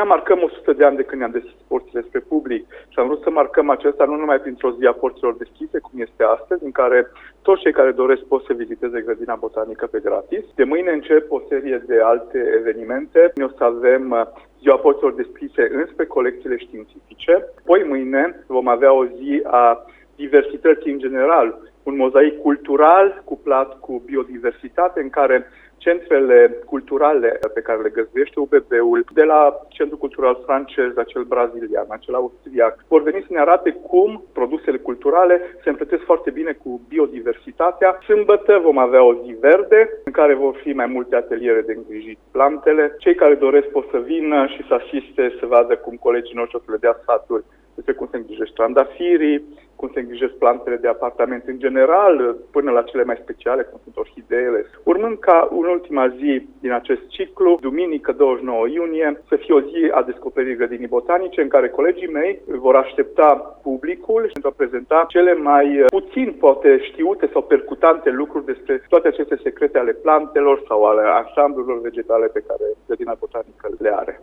invitat la Radio Cluj, despre programul evenimentelor: